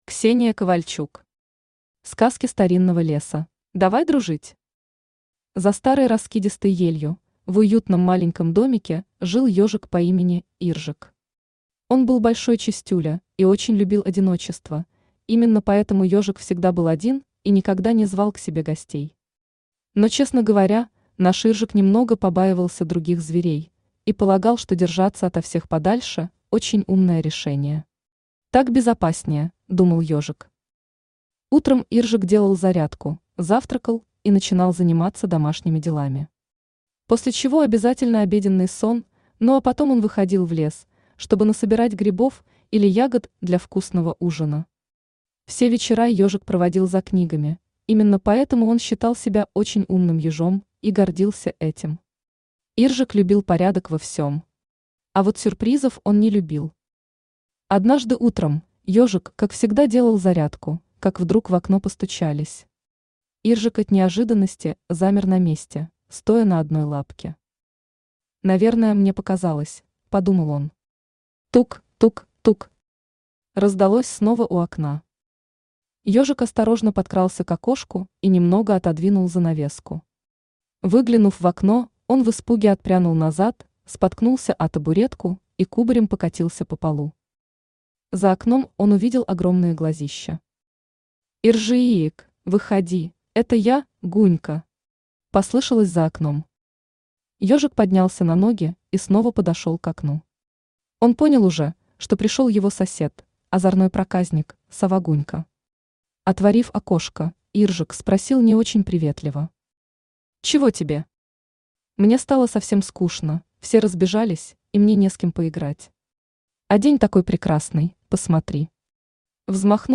Аудиокнига Сказки старинного леса | Библиотека аудиокниг
Читает аудиокнигу Авточтец ЛитРес.